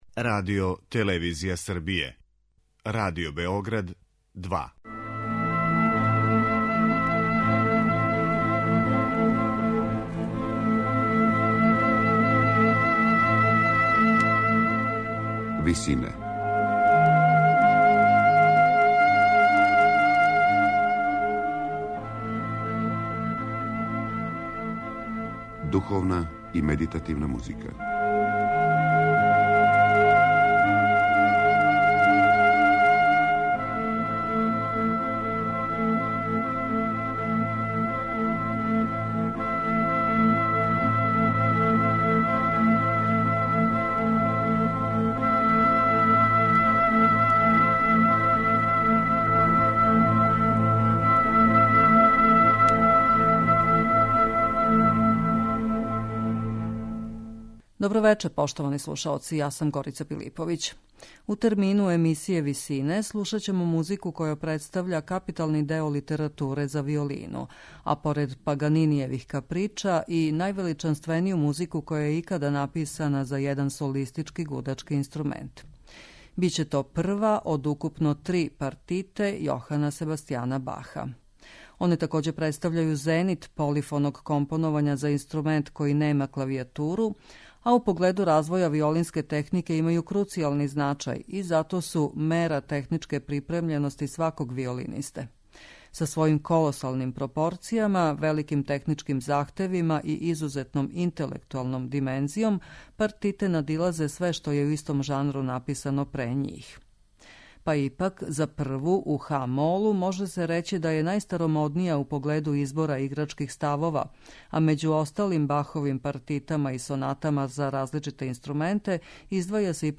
Слушаћете Прву Бахову партиту за виолину
Три партите Јохана Себастијана Баха, поред Паганинијевих Каприча, представљају највеличанственију музику која је икада написана за соло виолину.